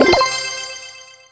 ulti_button_press_01.wav